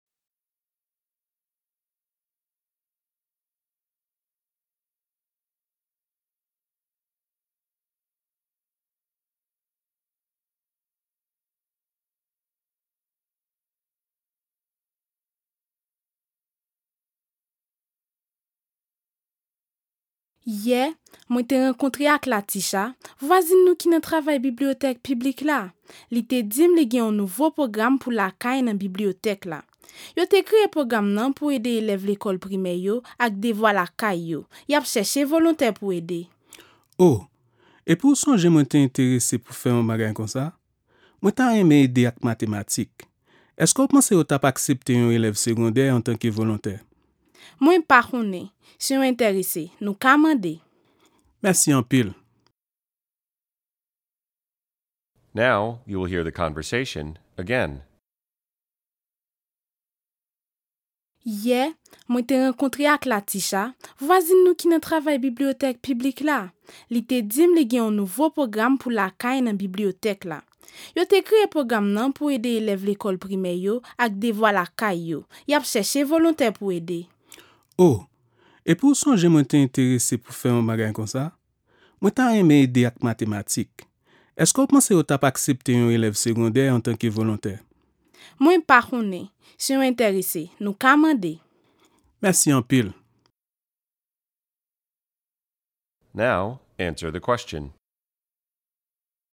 Listen to the conversation between a caregiver and a student; then answer the question.
[After 20 seconds of silence, the examinee will hear the audio recording below, which includes a 20-second pause at the beginning:]